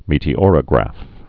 (mētē-ôrə-grăf, -ŏr-)